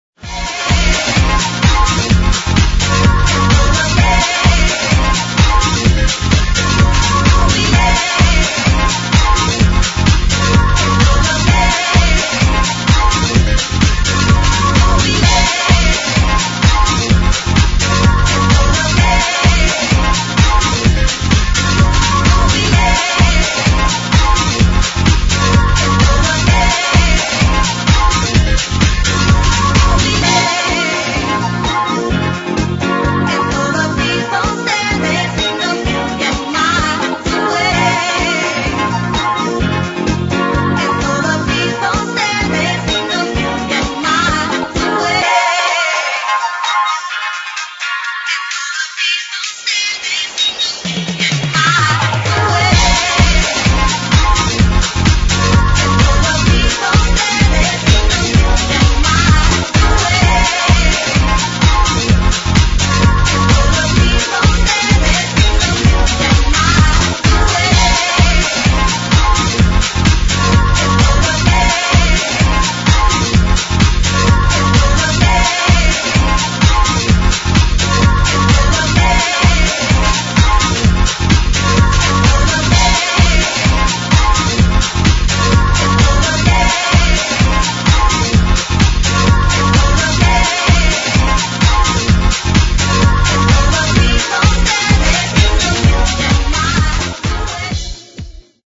Style: House